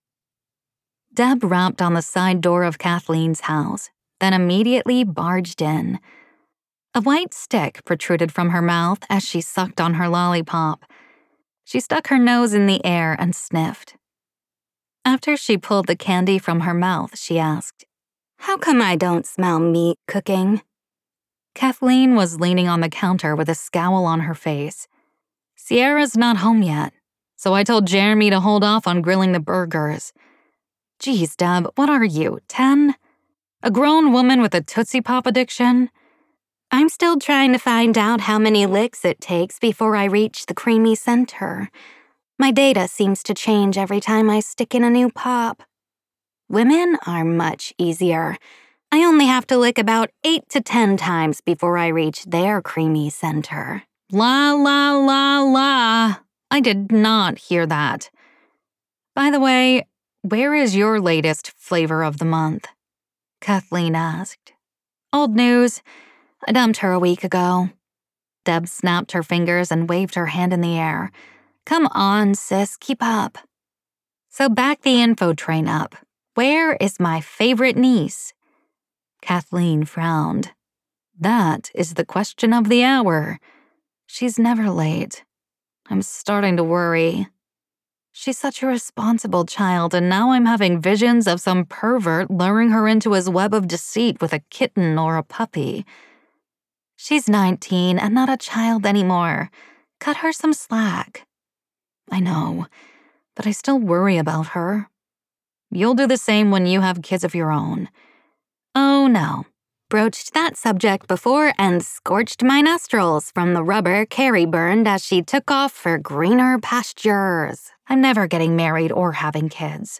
[Audiobook]
Uunconventional-Lovers-audio-promo.mp3